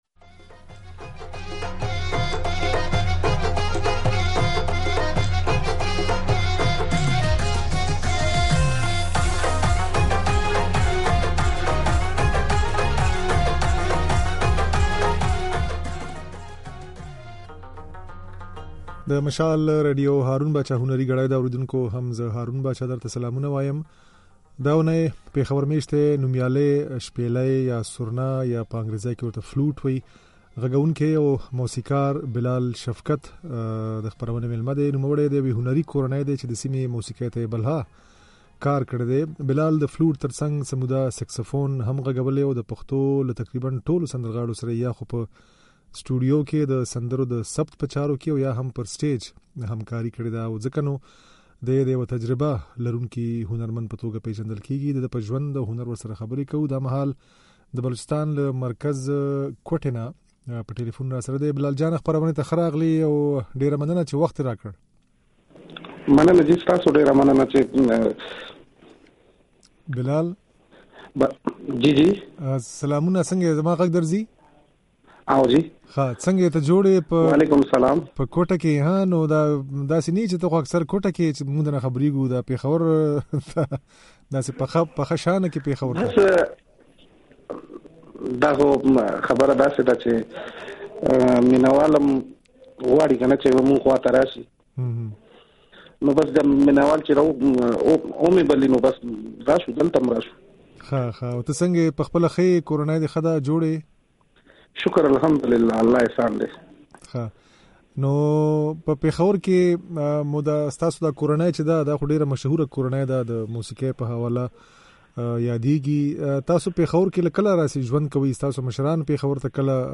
د نوموړي دا خبرې او غږولې ځينې نغمې يې په خپرونه کې اورېدای شئ.